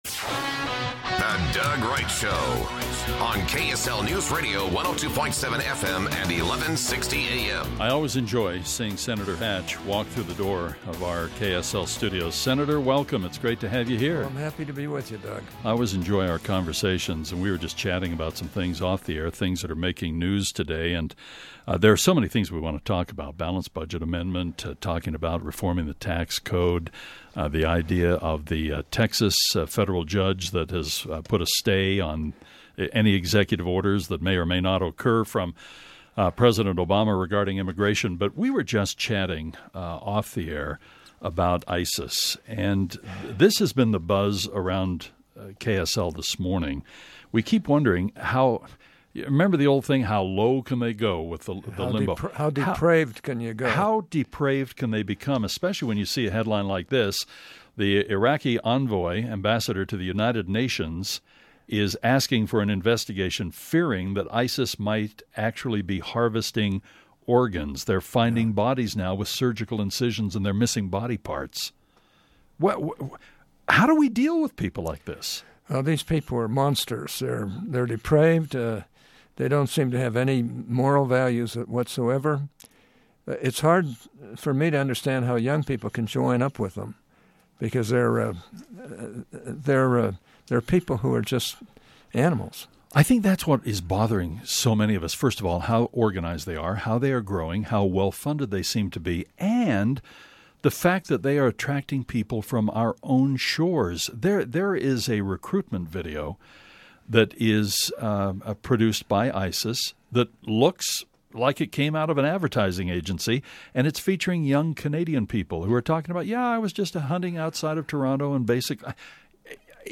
Iraqi authorities have discovered bodies located in shallow graves that have been found missing organs. We spoke with Senator Orrin Hatch about this as well as a discussion on Immigration and tax reform.